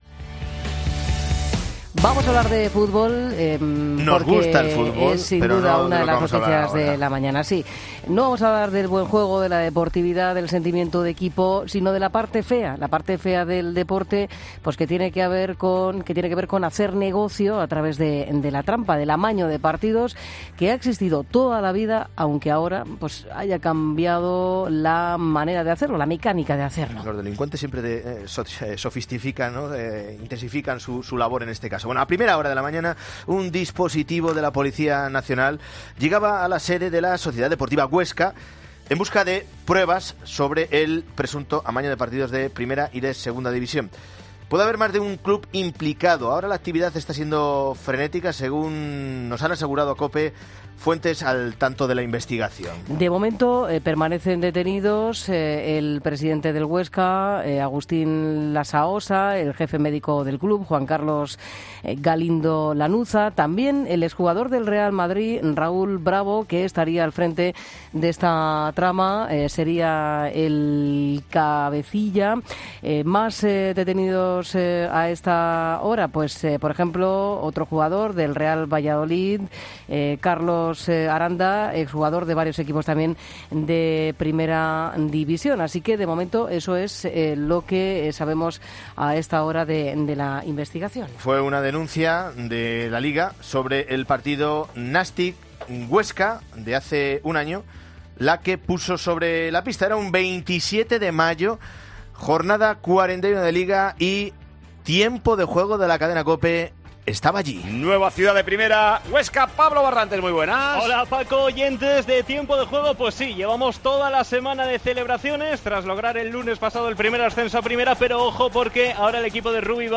Para aclarar todas las dudas que nos surgen sobre este tema ha pasado por los micrófonos de Mediodia COPE el director de El Partidazo de Cope, Juanma Castaño.